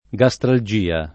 gastralgia [ g a S tral J& a ]